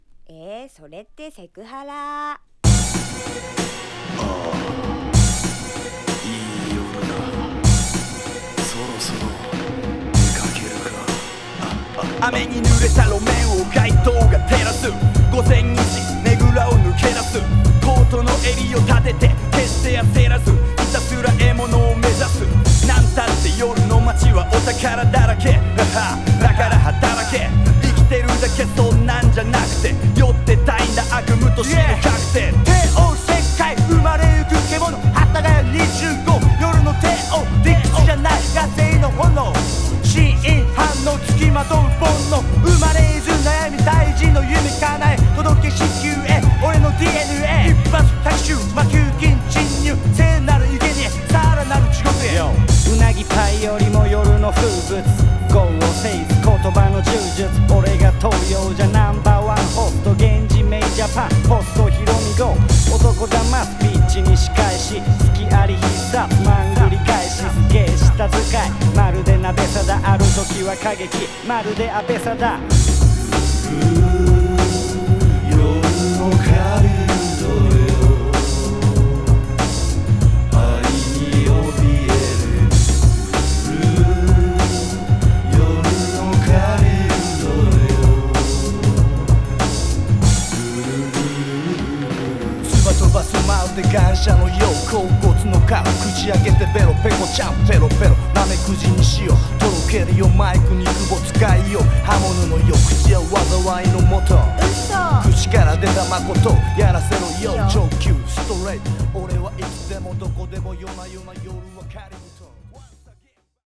(ヴォーカル)